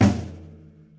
描述：Mid tambourine
Tag: 巴西 海洋 打击乐器 铃鼓